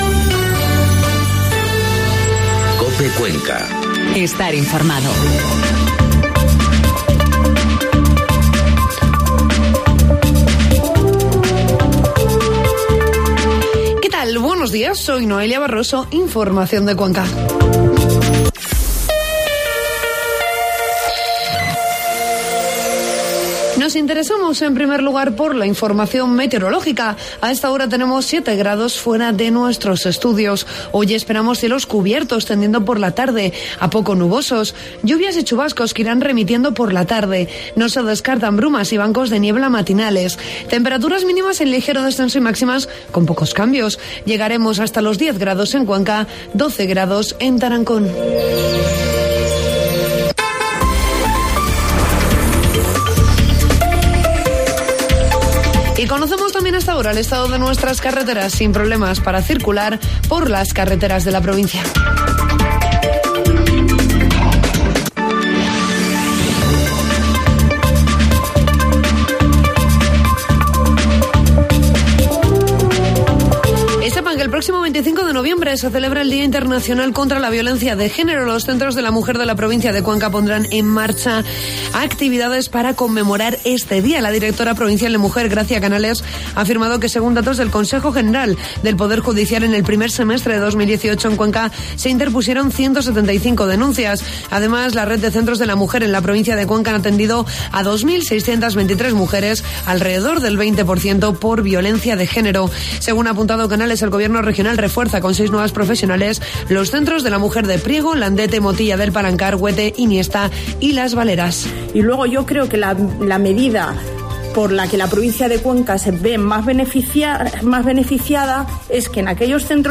Informativo matinal COPE Cuenca 23 de noviembre